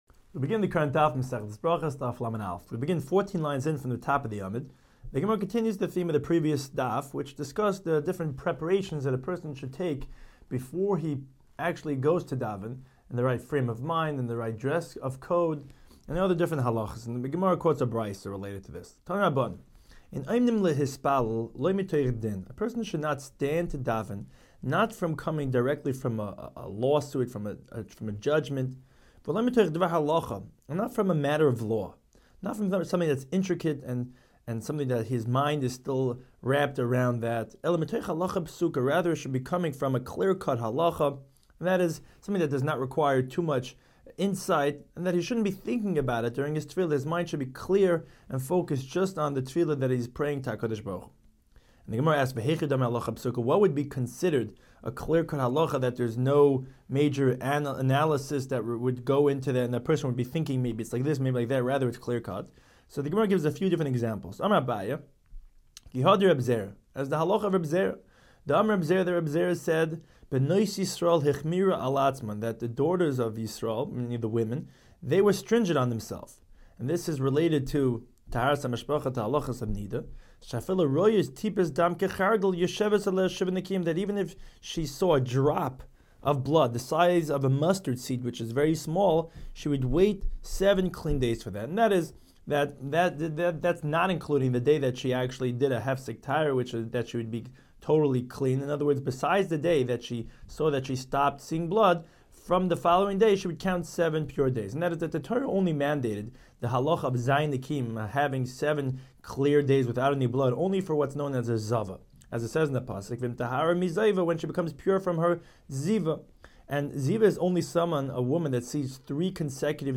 Daf Hachaim Shiur for Berachos 31